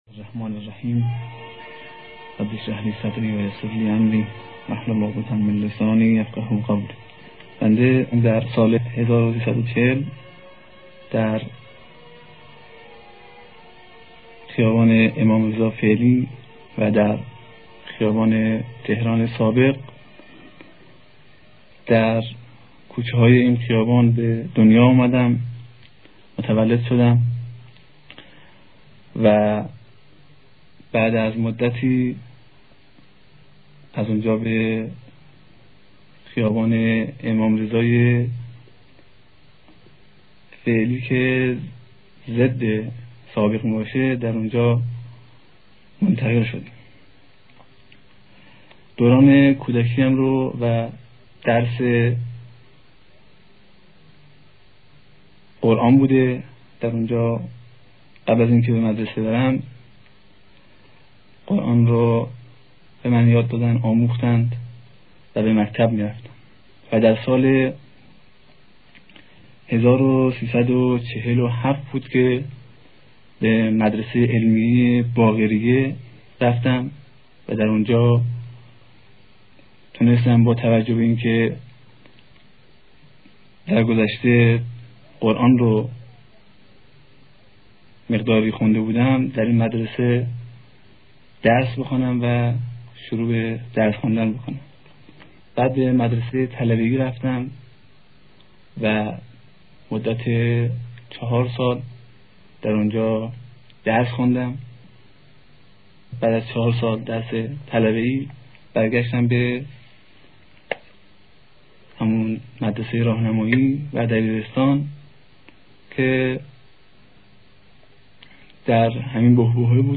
بیوگرافی شهید محمود کاوه از زبان خود شهید
فایل صوتی صدای شهید کاوه: